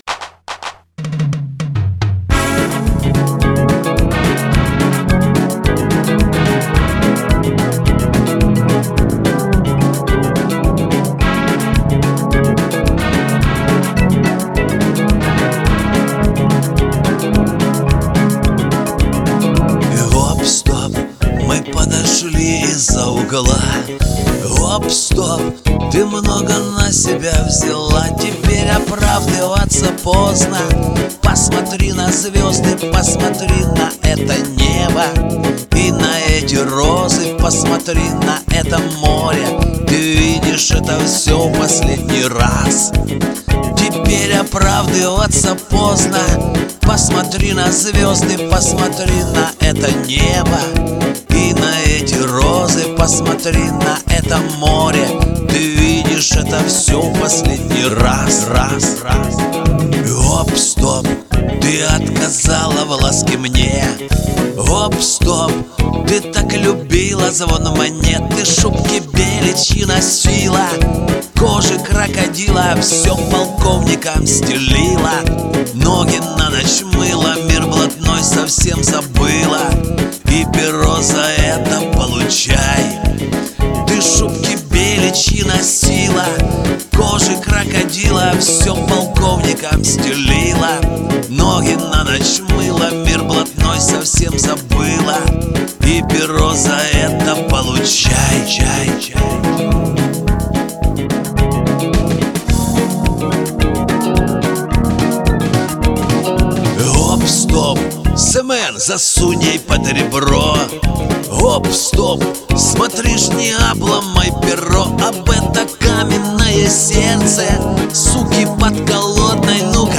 ПЕСНЯ ОТВЯЗНАЯ,ДРАЙВОВАЯ!РЕСПЕКТ ОБОИМ!!!!УДАЧИ!!!